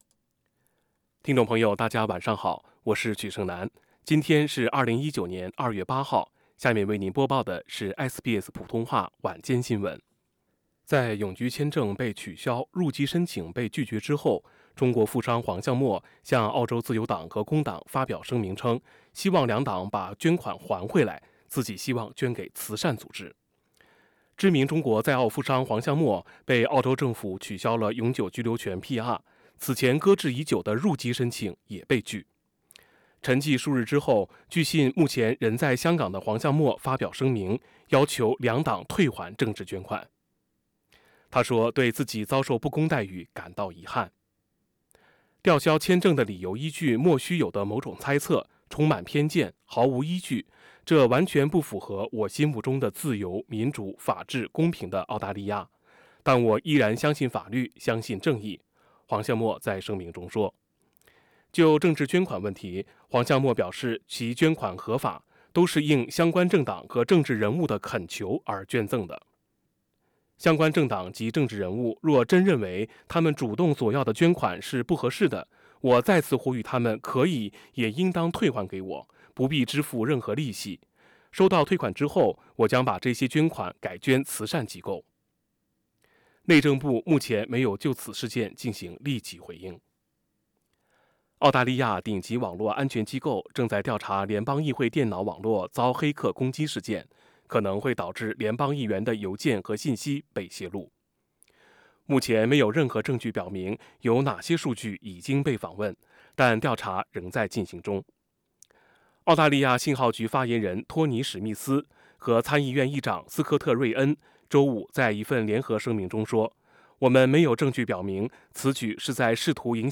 SBS晚新聞 （2月8日）
evening_news_feb_8.mp3